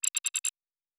pgs/Assets/Audio/Sci-Fi Sounds/Interface/Error 08.wav at master
Error 08.wav